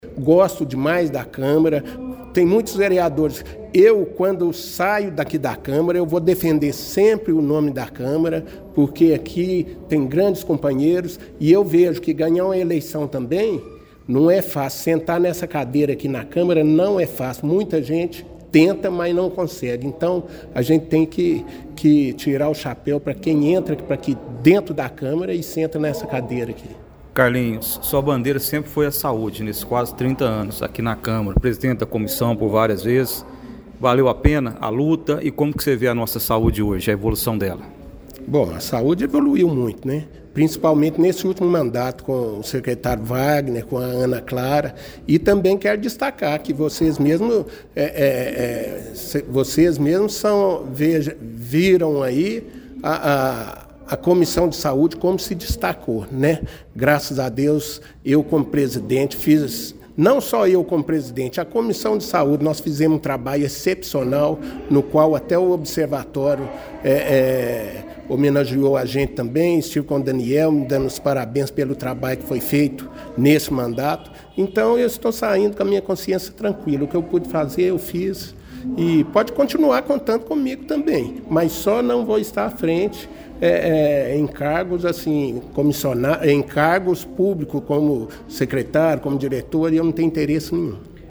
A Câmara Municipal de Pará de Minas realizou na tarde desta terça-feira, 19 de novembro, a 40ª reunião semanal ordinária no exercício de 2024.